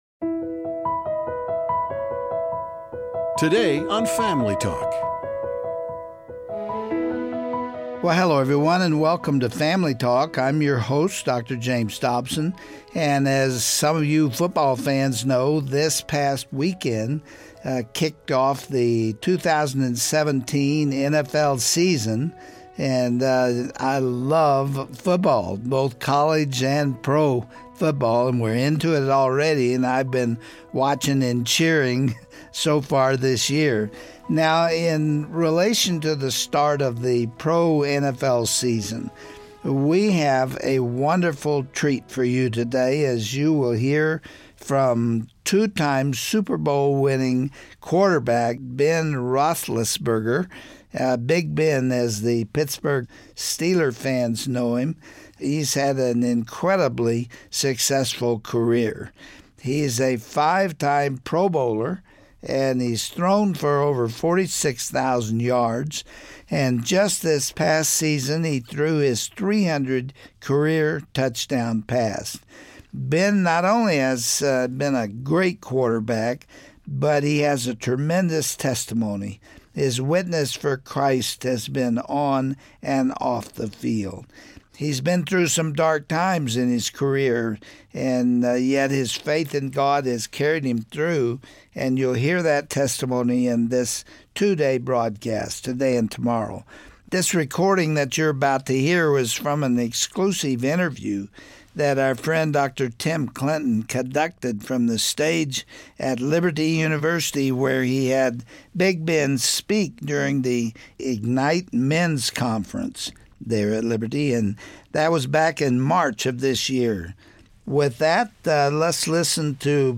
Today Family Talk is excited to bring an exclusive interview with two-time Super Bowl winning quarterback Ben Roethlisberger.